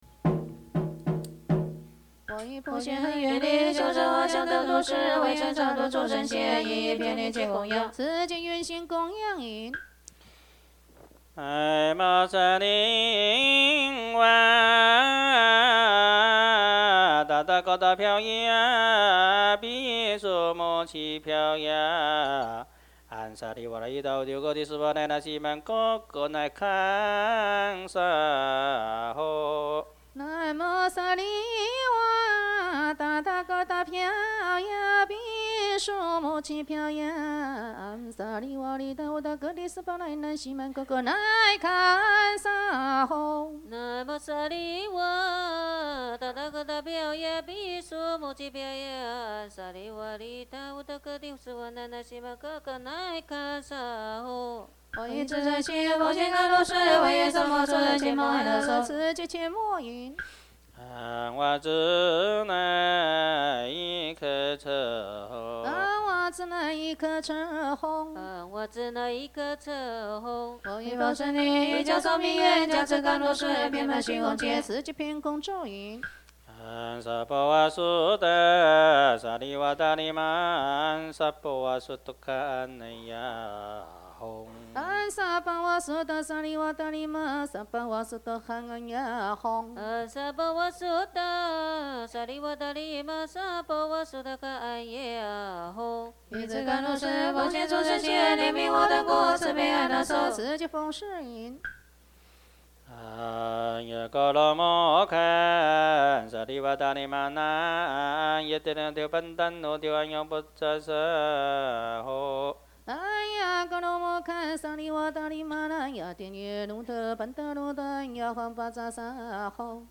佛教音樂  Mp3音樂免費下載 Mp3 Free Download